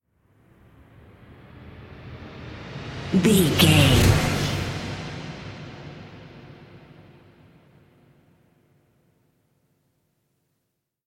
Atonal
synthesiser
percussion
ominous
dark
suspense
haunting
tense
creepy